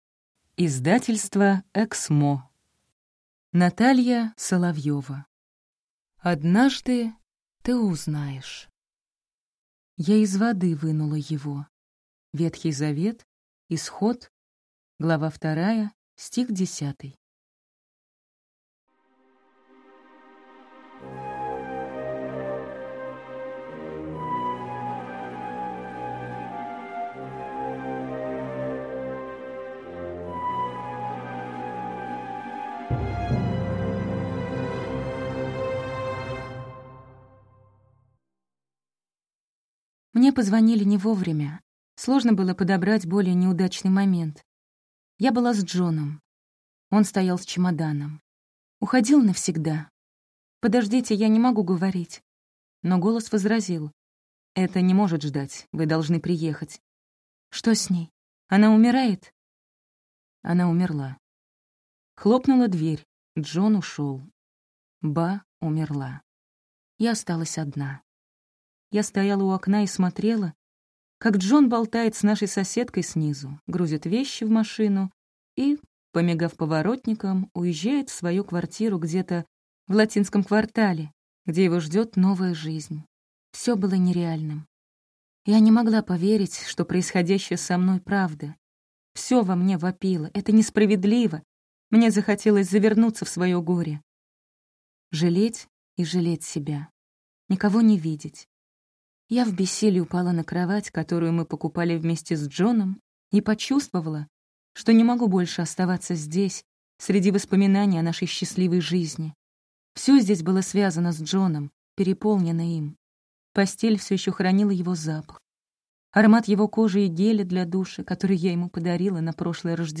Аудиокнига Однажды ты узнаешь | Библиотека аудиокниг